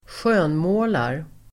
Ladda ner uttalet
Uttal: [²sj'ö:nmå:lar]